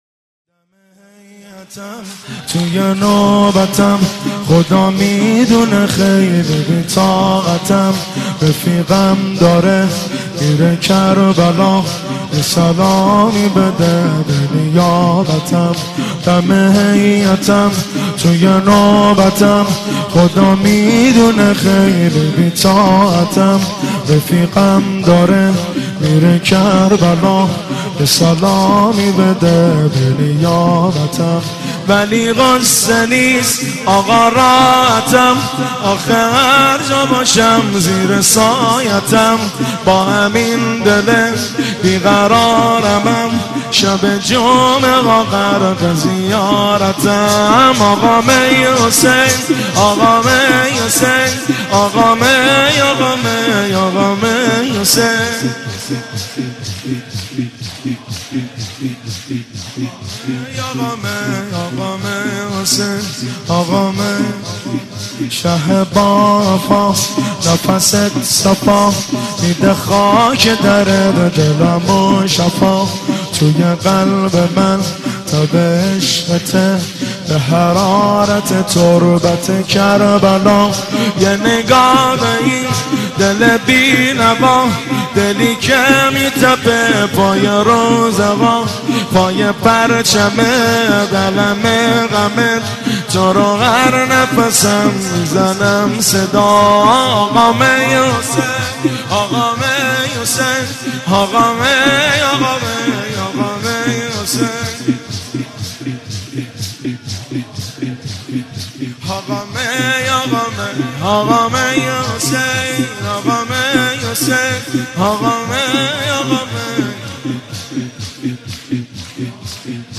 مداحی
در شب 27 صفر 1394 در هیئت بین الحرمین